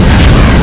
RcktFly1.ogg